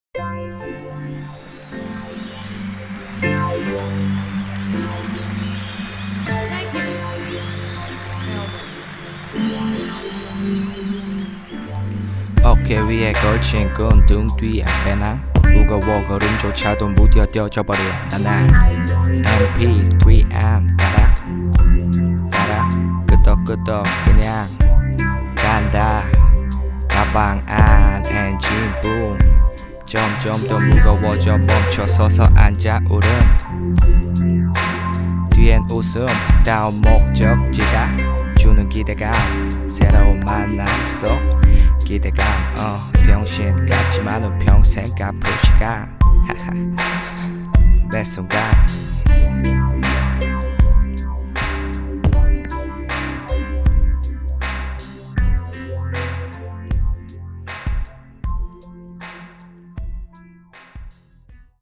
• [REMIX.]